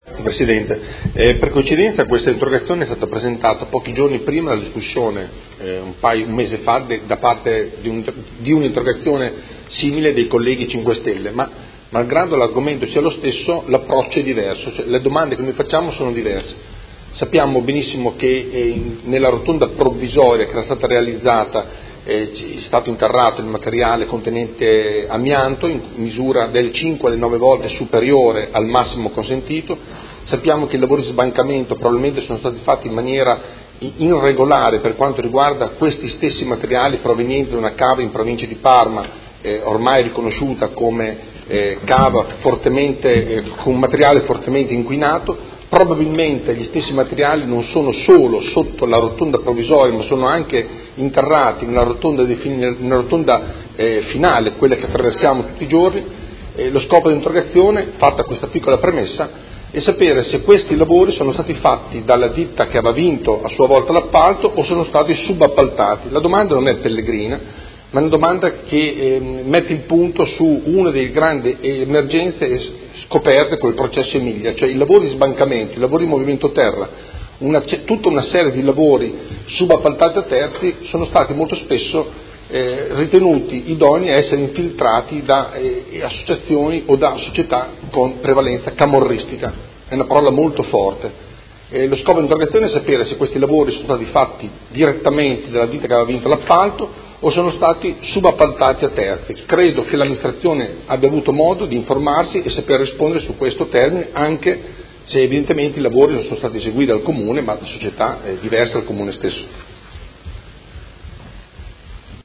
Seduta dell'11/01/2018 Interrogazione del Consigliere Galli (FI) avente per oggetto: La rotonda di Via Emilia Est contiene amianto; chi ha realizzato i lavori? La ditta aggiudicatrice o ditte terze subappaltatrici?